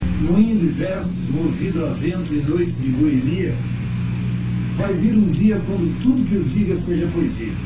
clique para ouvir ouça Leminski neste poema (real audio 15,3kb)